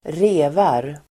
Uttal: [²r'e:var]